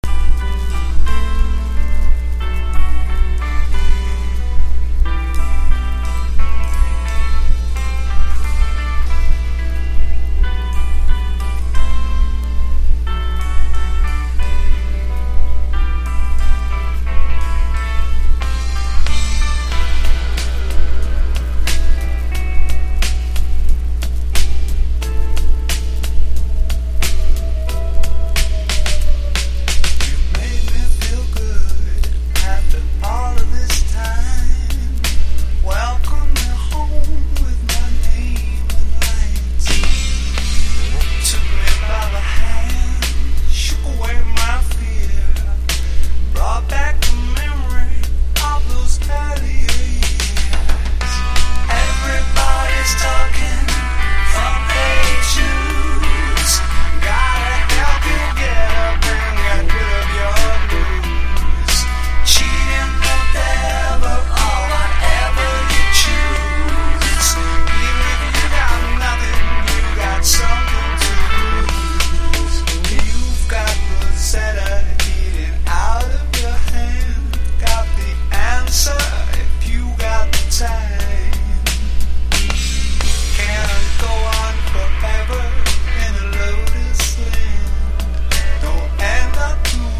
アメリカで録音しながらも、メロディアスでリズムの立ったブリティシュ色強めの作品。